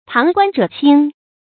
注音：ㄆㄤˊ ㄍㄨㄢ ㄓㄜˇ ㄑㄧㄥ
旁觀者清的讀法